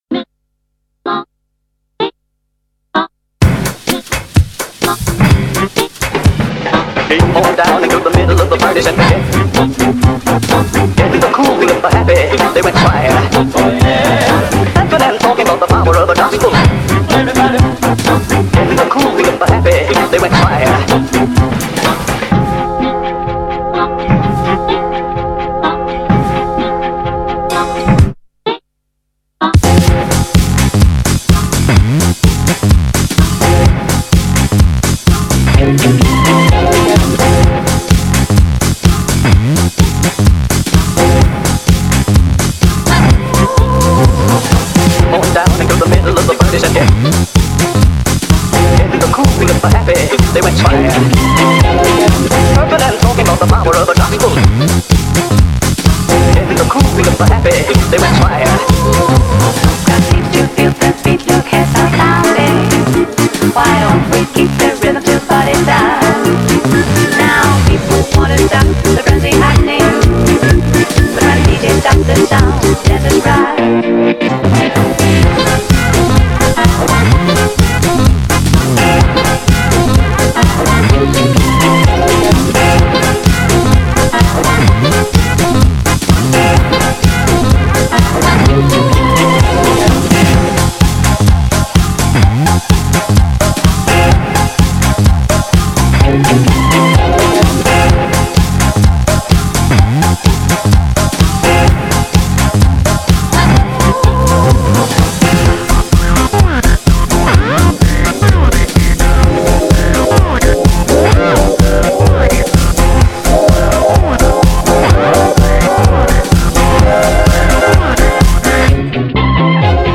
BPM63-127
Audio QualityMusic Cut